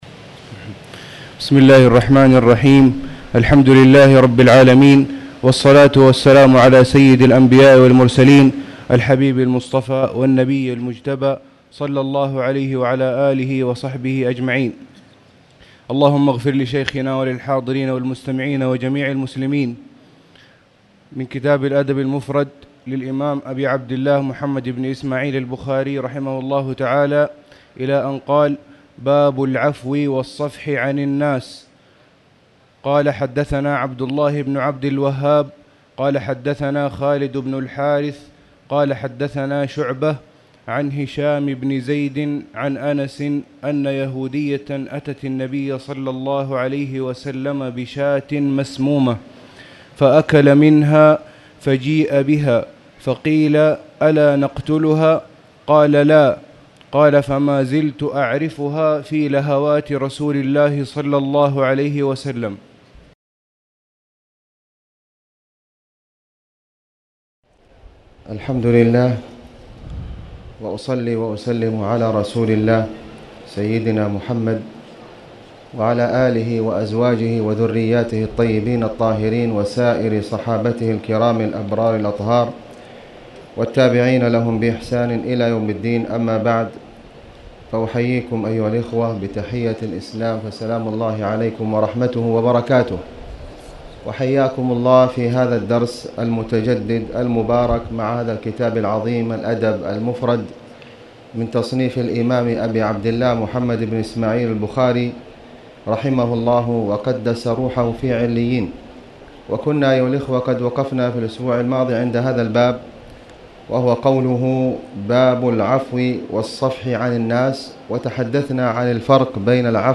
تاريخ النشر ٢٨ صفر ١٤٣٨ هـ المكان: المسجد الحرام الشيخ: خالد بن علي الغامدي خالد بن علي الغامدي باب العفو والصفح عن الناس